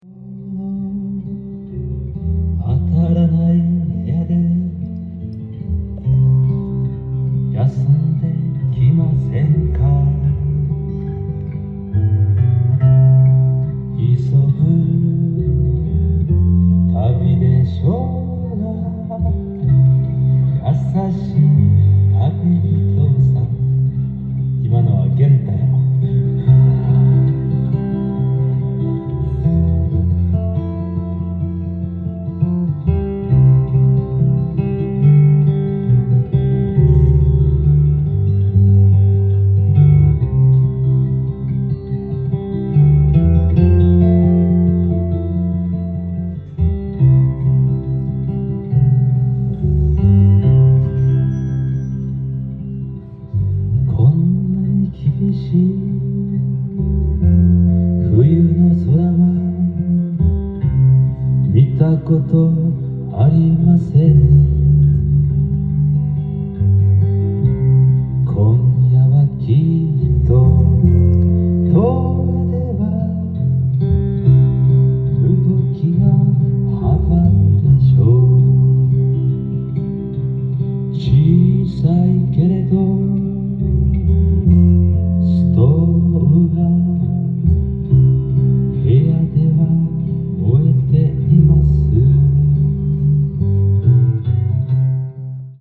ジャンル：FOLKSONG
店頭で録音した音源の為、多少の外部音や音質の悪さはございますが、サンプルとしてご視聴ください。